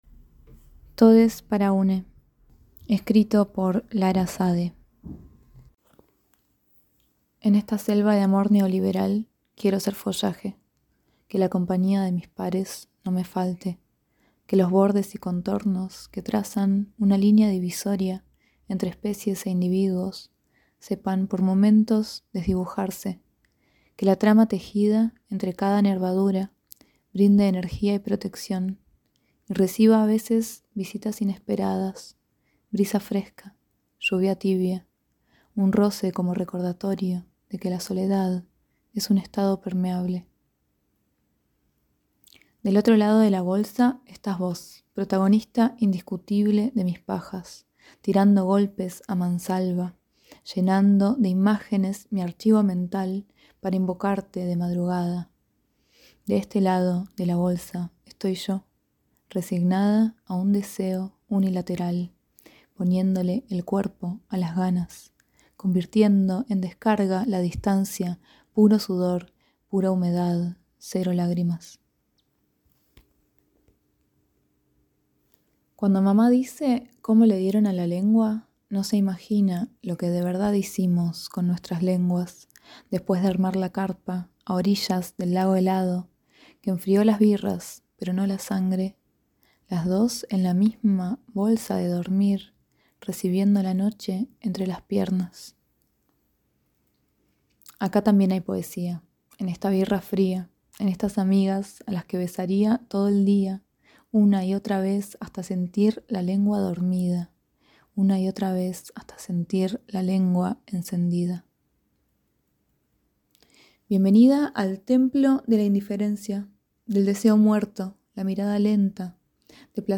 Fanzine con audio-narraciòn por autora